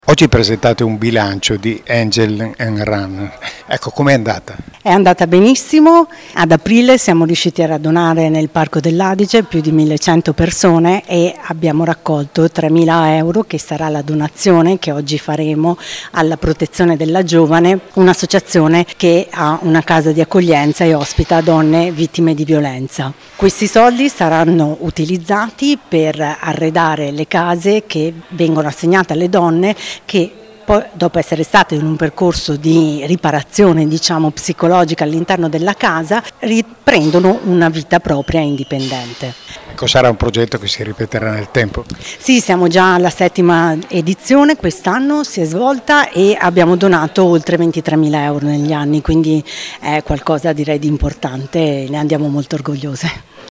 Le dichiarazioni raccolte dal nostro corrispondente